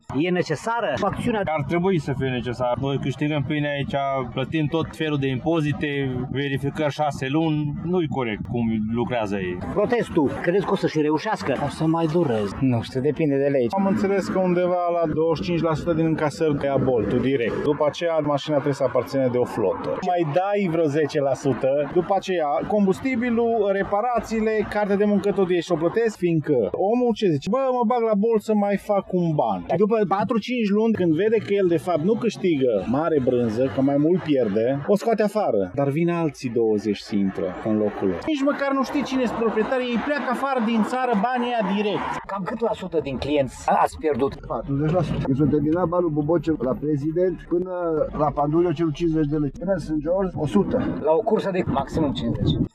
Taximetriștii târgumureșeni vor doar condiții egale de muncă cu cei care lucrează la firmele de ride-sharing: